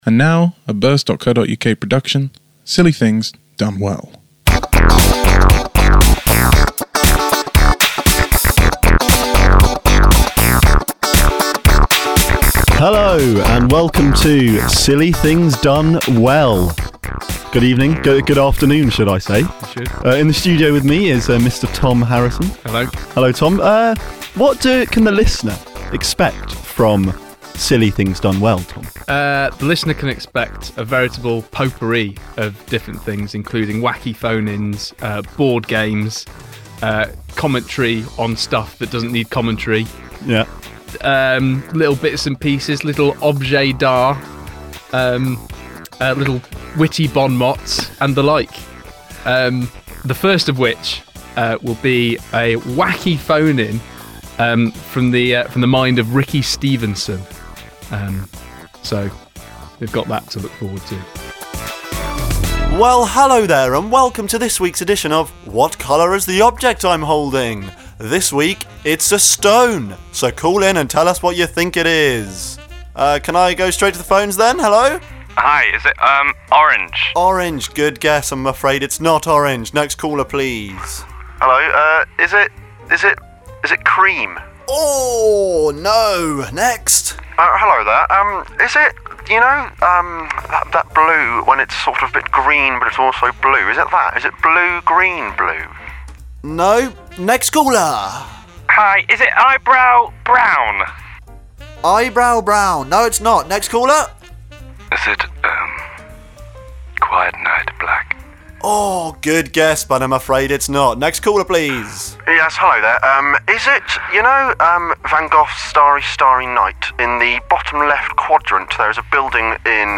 BIRSt takes a sideways look at radio features in this hour of fun and games.